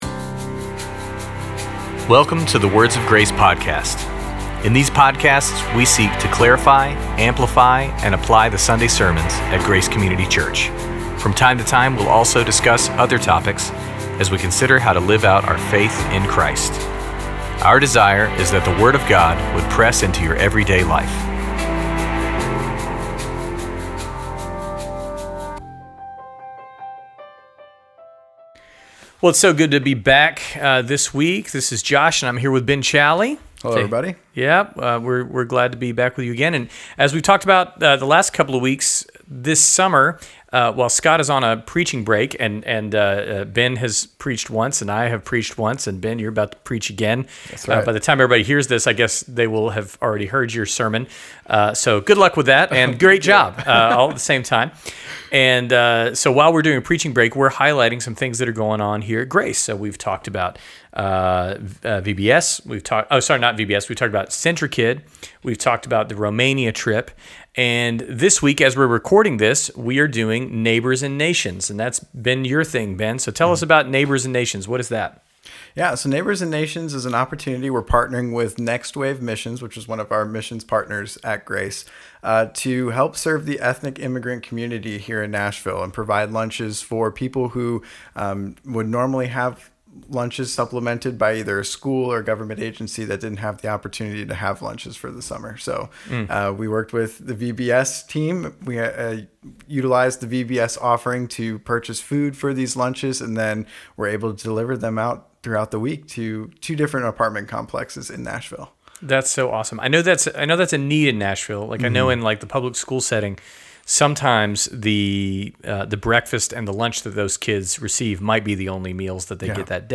hear interviews form church members who participated.